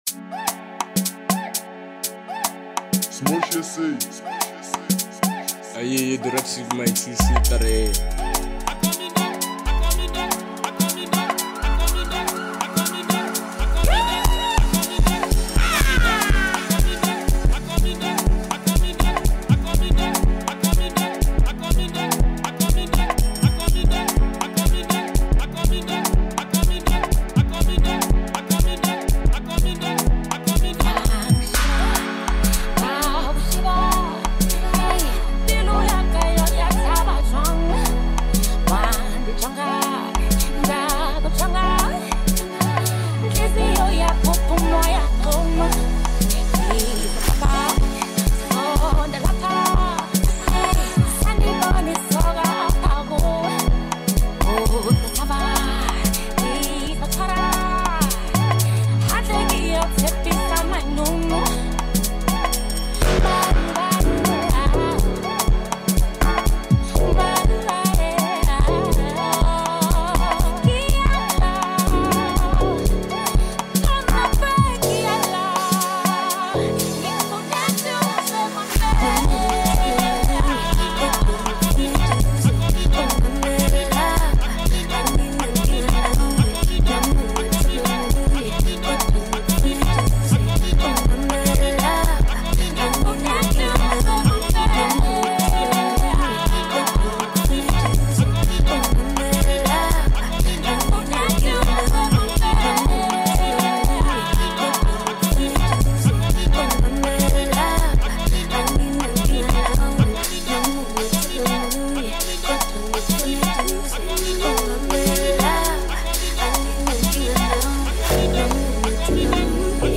Home » Hip Hop » Amapiano » DJ Mix